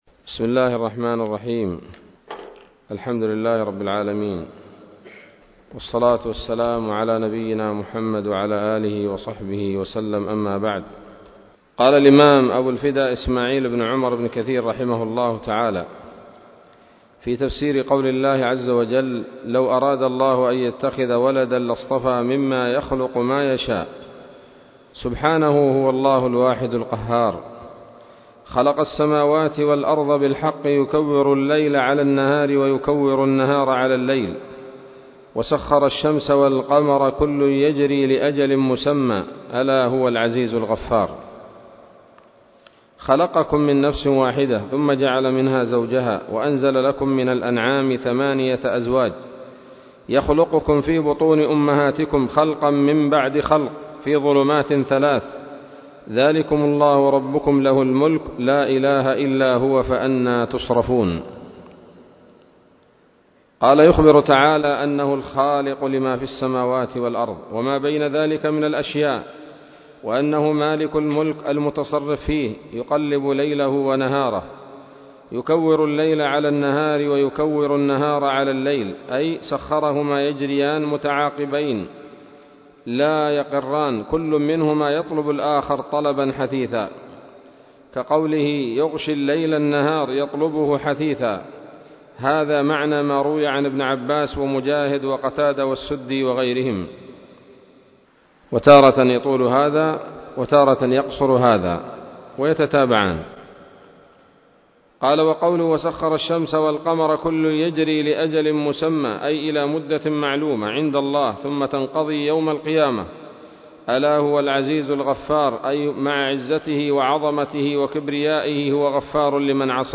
الدرس الثاني من سورة الزمر من تفسير ابن كثير رحمه الله تعالى